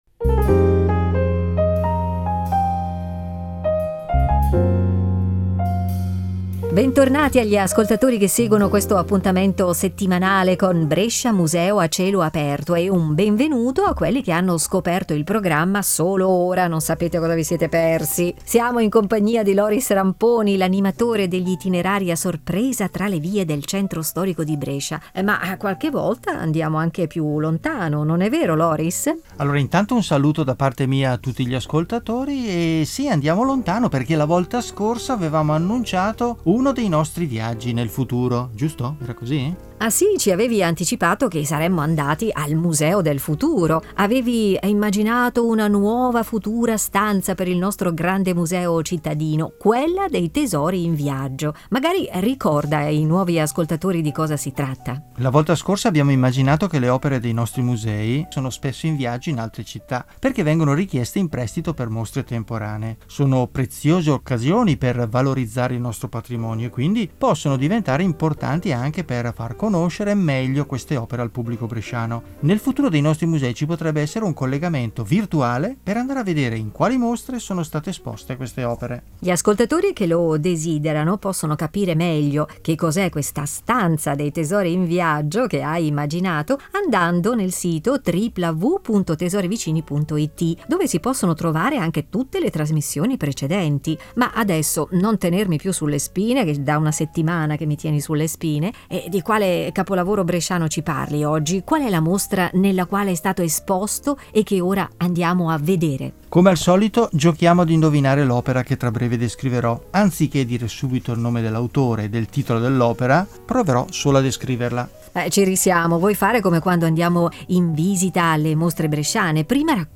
audio-guida e itinerari per passeggiare tra i musei e le mostre